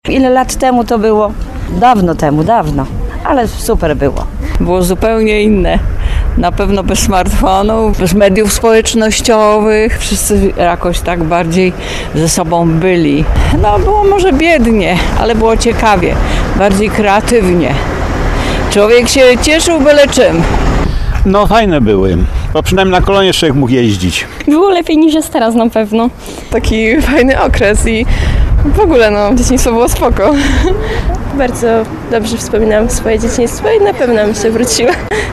’Bardzo dobrze wspominam dzieciństwo’, 'Było ciekawie, bardziej kreatywnie’, 'Było lepiej’ – tak tarnowianie wspominają swoje dzieciństwo.